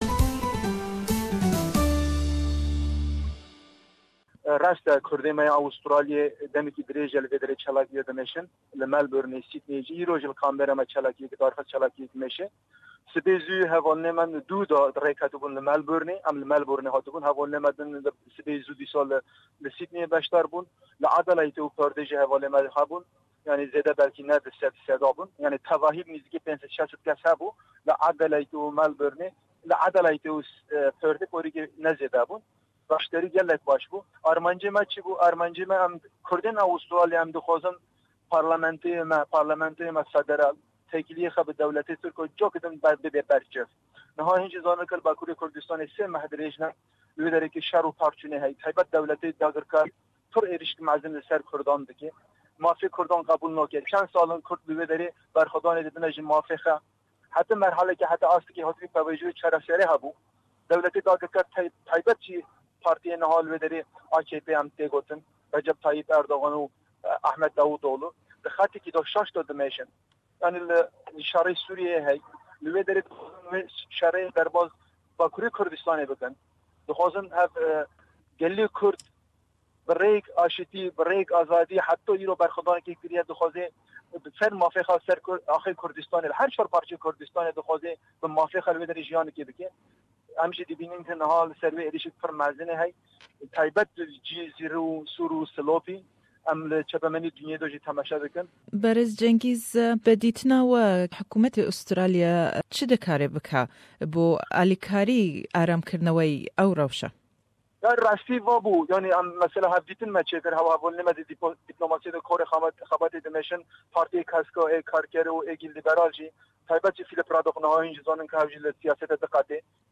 Le em hevpeyvîne da